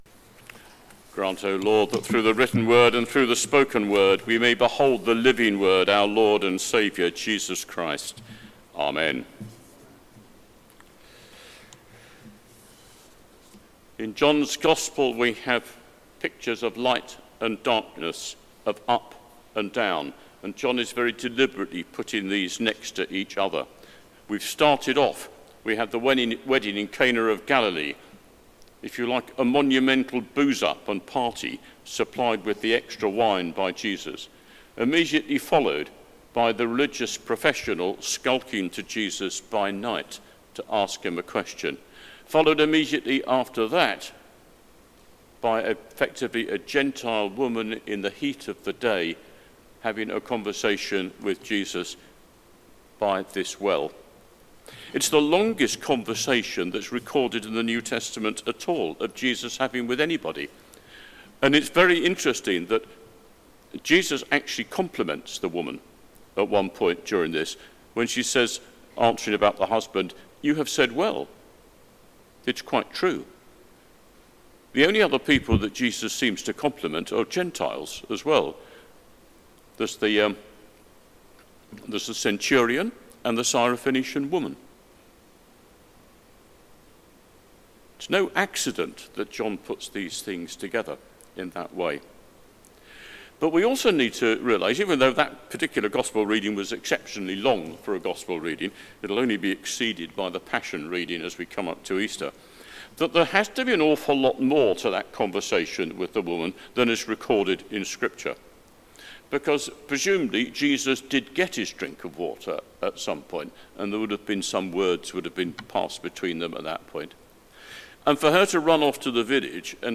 Sermon: Bringing our failures into the light | St Paul + St Stephen Gloucester